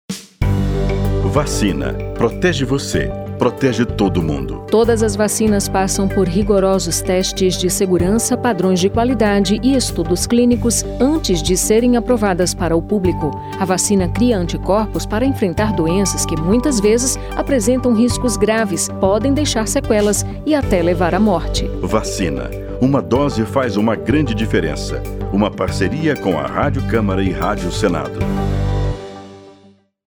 06-spot-segurana-camara-com-senado.mp3